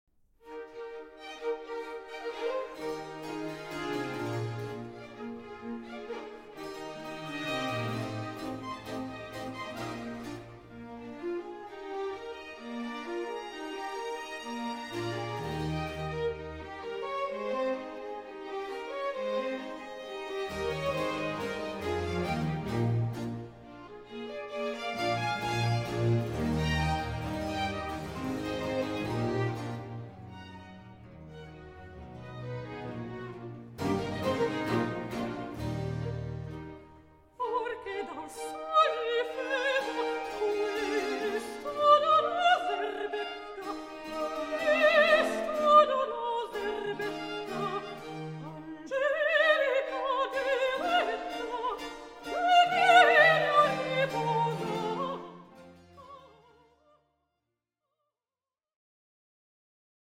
baroque repertoire